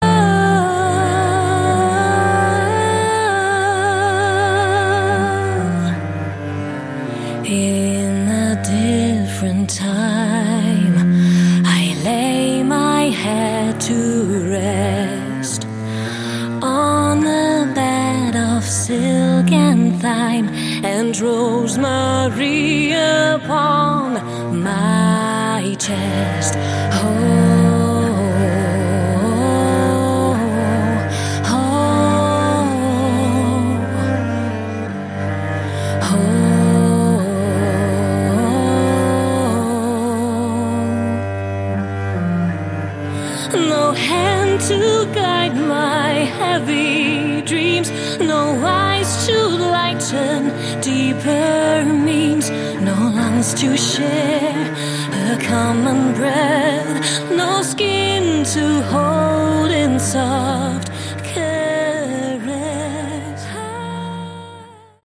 Category: Melodic Prog Metal
vocals
violin
keyboards
guitar
drums
cello, backing vocals
bass